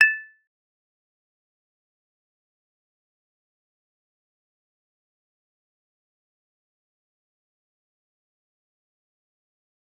G_Kalimba-A6-f.wav